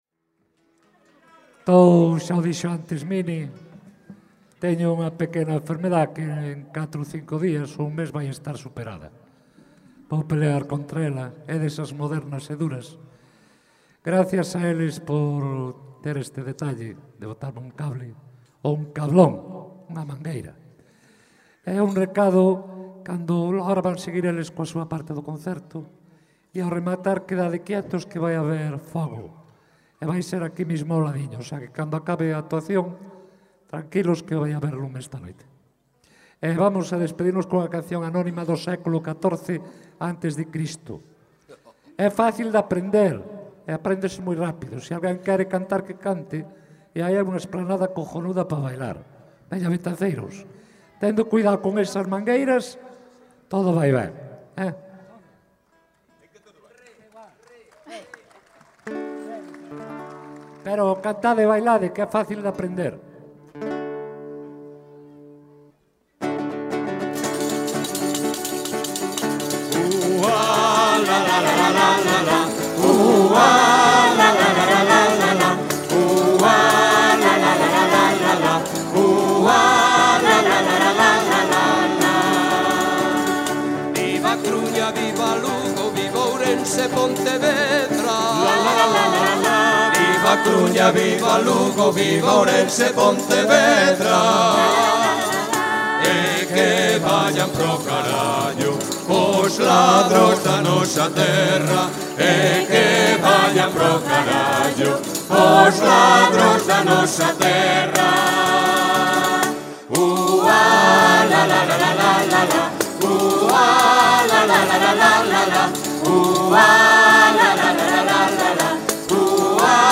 Letra: Popular
Música:Popular